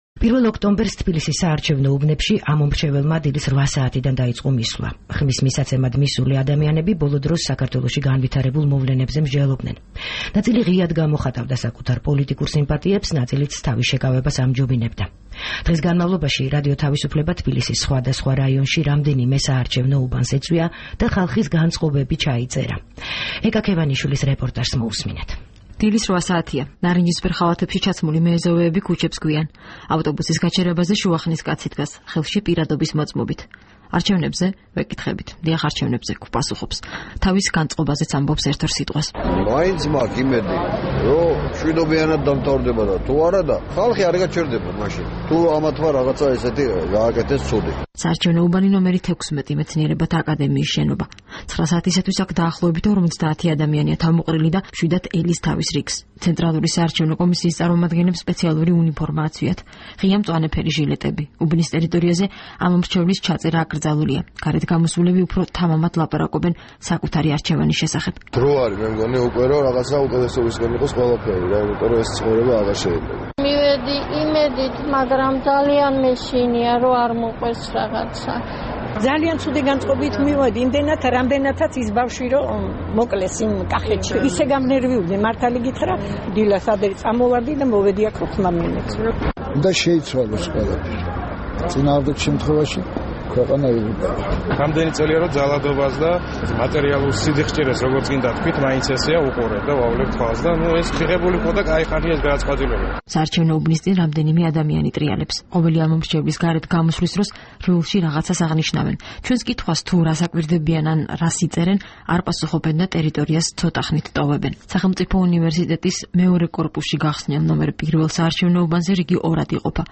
რეპორტაჟი საარჩევნო უბნებიდან